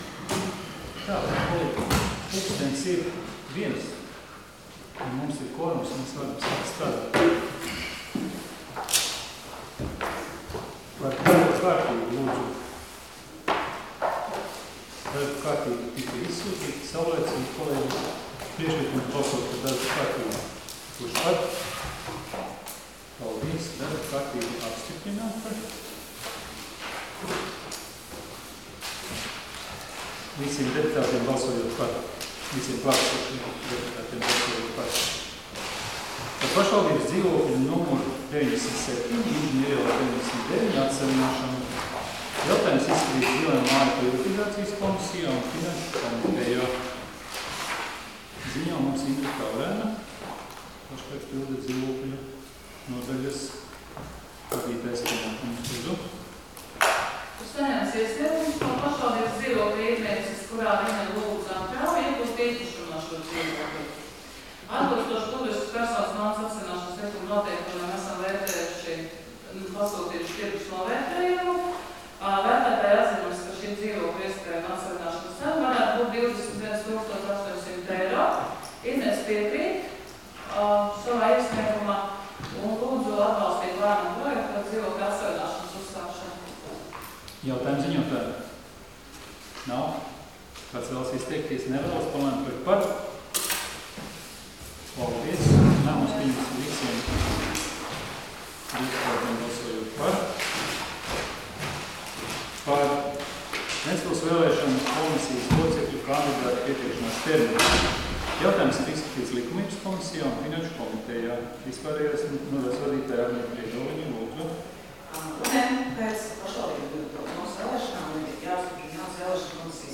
Domes sēdes 17.01.2020. audioieraksts